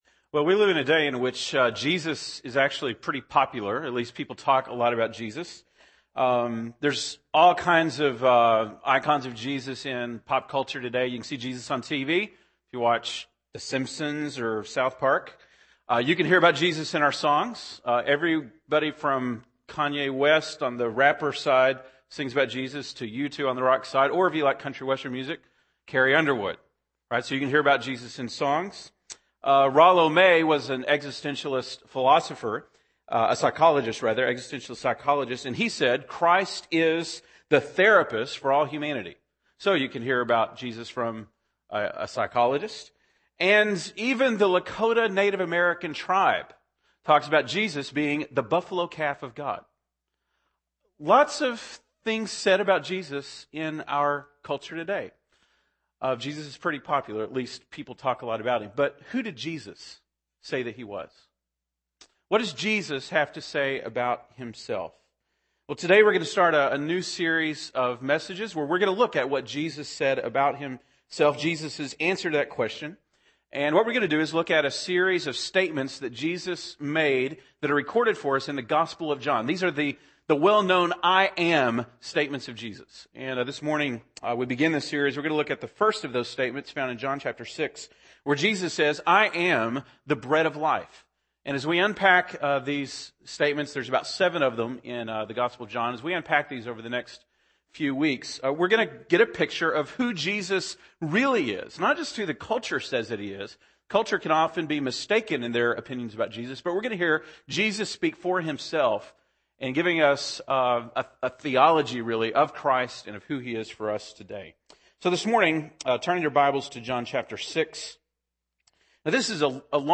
November 7, 2010 (Sunday Morning)